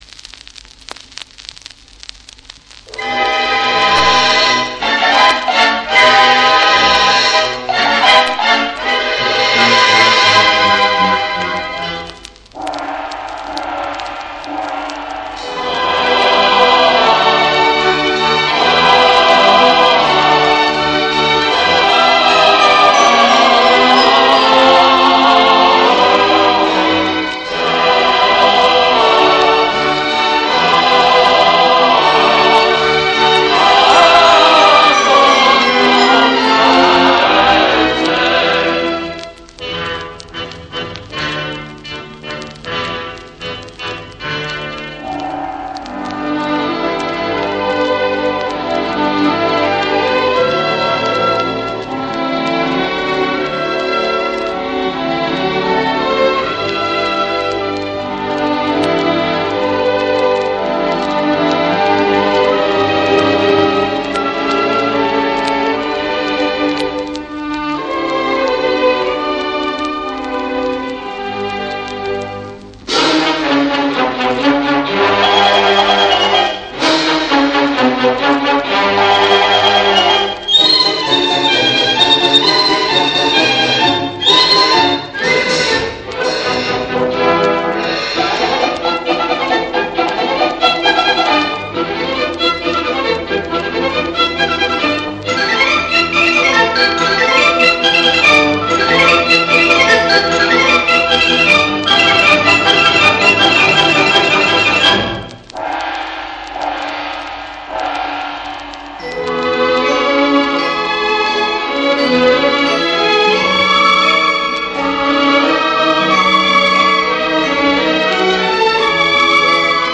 Lacks cor anglais. With chanting but no shouting